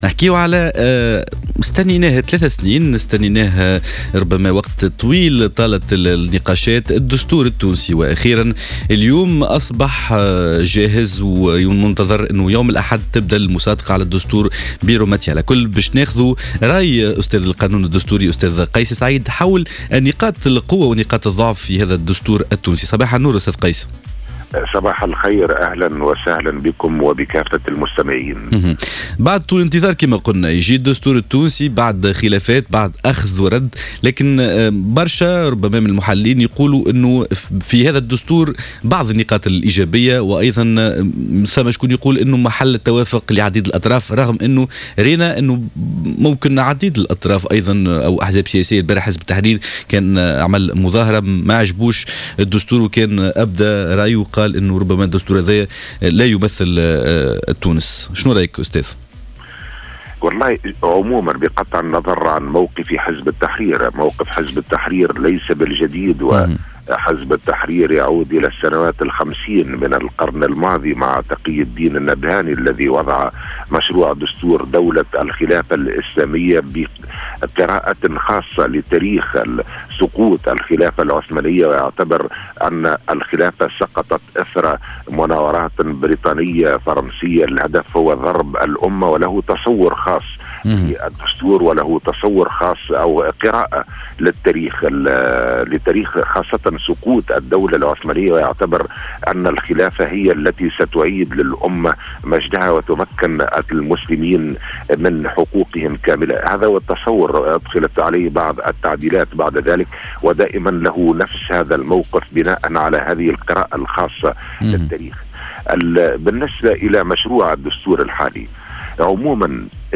Kais Saïd, professeur en droit constitutionnel, a critiqué dans une intervention sur les ondes de Jawhara FM dans le cadre de l’émission « Sbeh el Ward » du samedi 25 janvier 2014, le projet de la nouvelle constitution tunisienne, indiquant qu’il est basé sur la « satisfaction » de certaines parties.